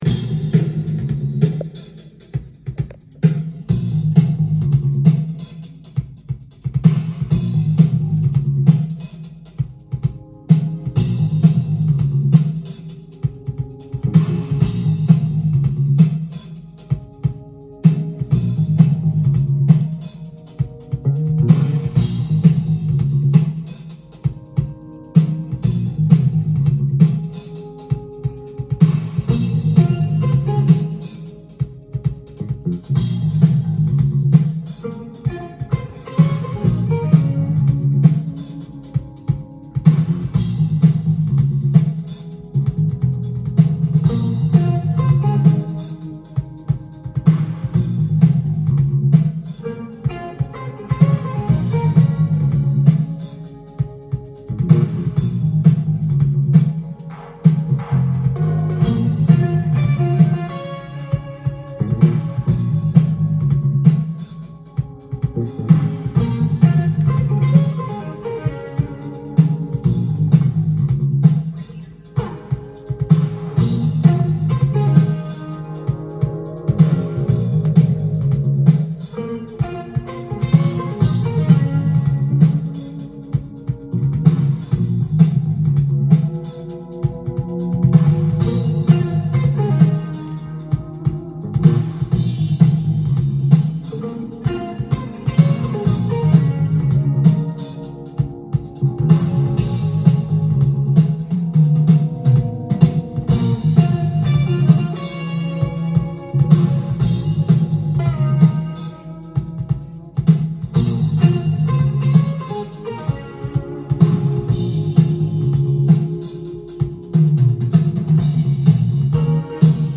LP
店頭で録音した音源の為、多少の外部音や音質の悪さはございますが、サンプルとしてご視聴ください。
緊張感あふれるクールな質感と熱いインプロヴィゼーションのバランスが絶妙な傑作！！
音が稀にチリ・プツ出る程度